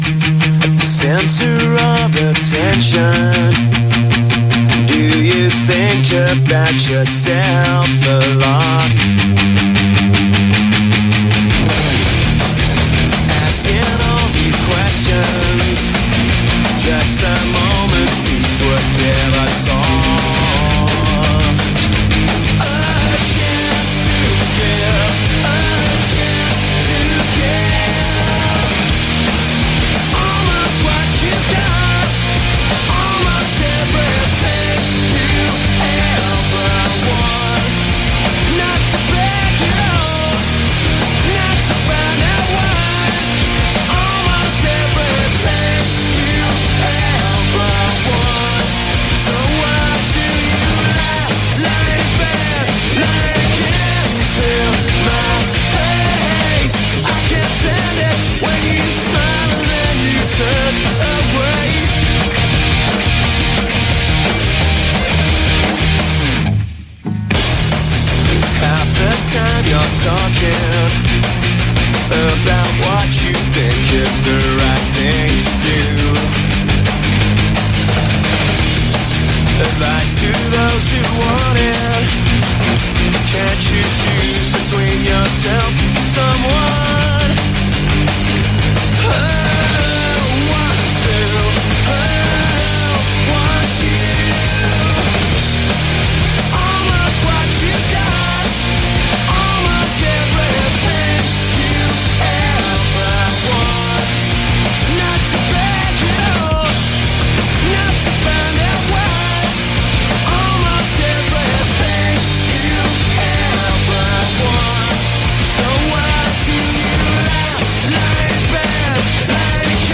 “Pop-Punk”